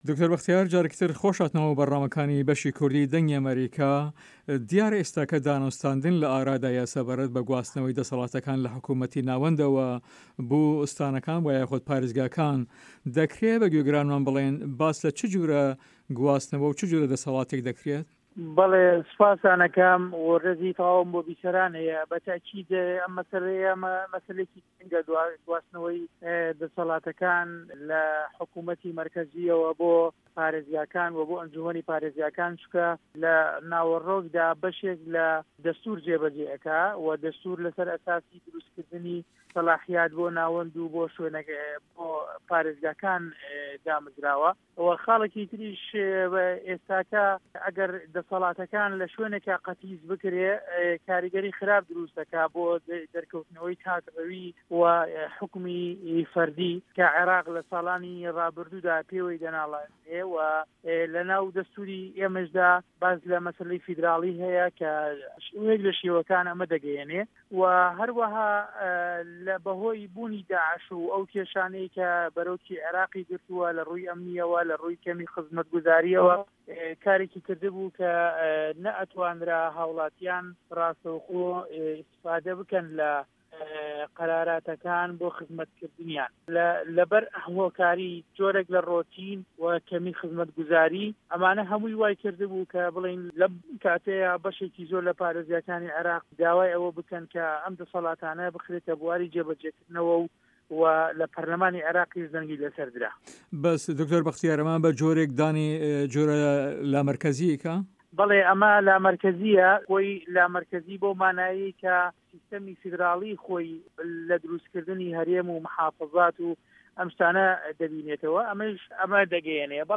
دکتۆر بەختیار شاوەیس ئەندام پەرلەمانی عێراق لەسەر لیستی یەکێتی نیشتمانی کوردستان لە هەڤپەیڤینێکدا لەگەڵ بەشی کوردی دەنگی ئەمەریکا دەلێت" ئەو گواستنەوە بەشێک لە دەستور جێ بە جێ ئەکات، لەسەر بناغەی دەسەڵاتەکان بۆ ناوەند و پارێزگاکان و بەو پێیەی ئەگەر پێت و دەسەڵاتەکان لە شوێنێکدا قەتیس بکرێت کاریگەری بەدی دەبێت بۆ دەرکەوتنەوەی تاک و فەرمانرەوایی تاکانە کە عێراق ساڵانێکە پێیەوە دەناڵێنێ.